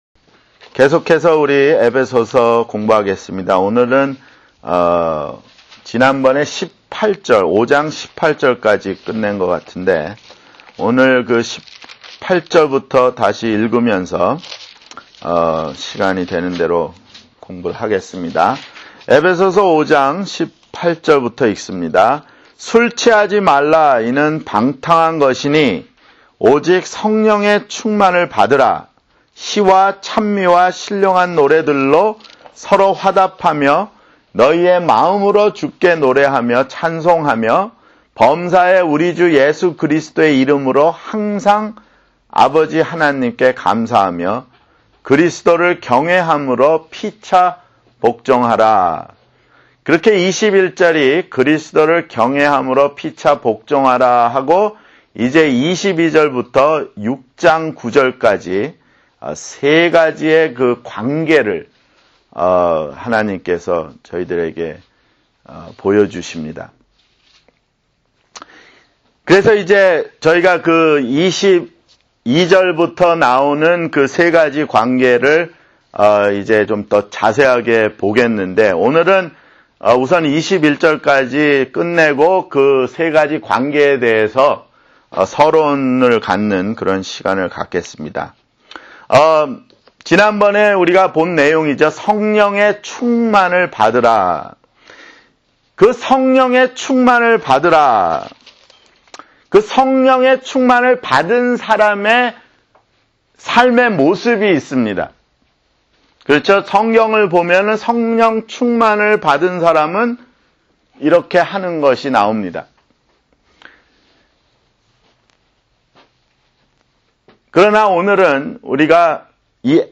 [성경공부] 에베소서 (59)